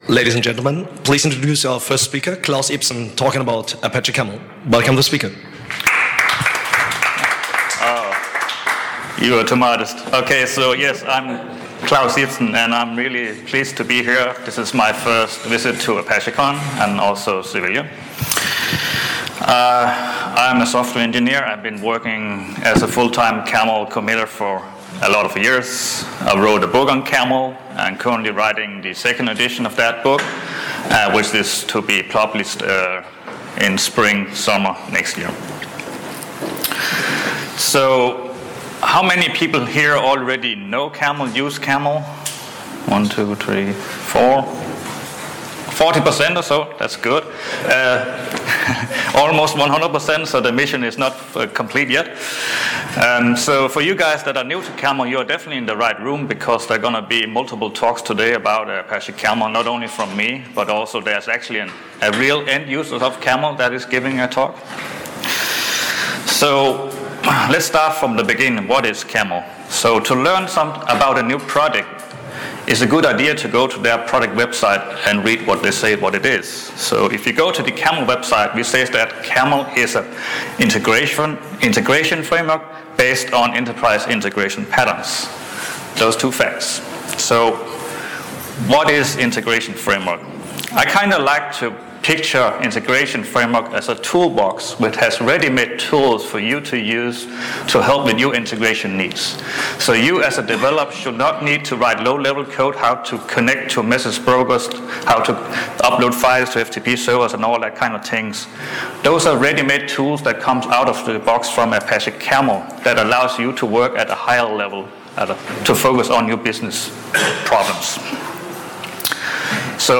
This talk is a mix between slides and live demo/coding.